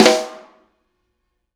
R_B Snare Flam - Close.wav